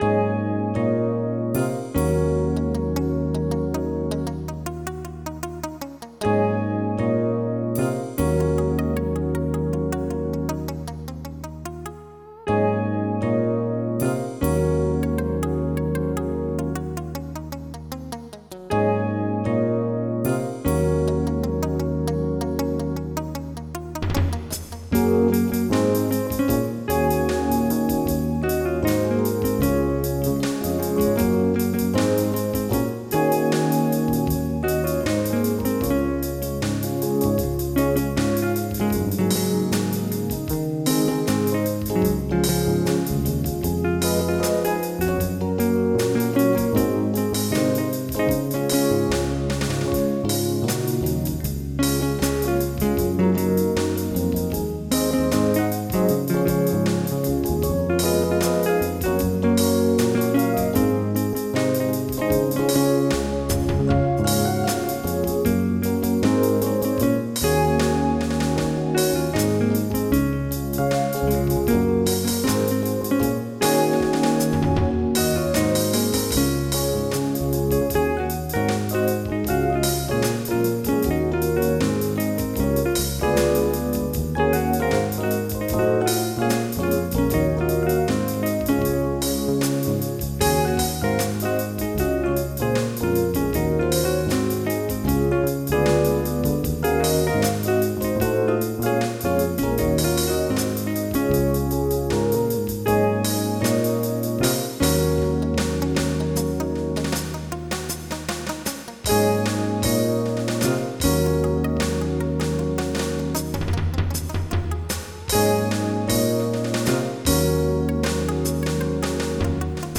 Jazz
MIDI Music File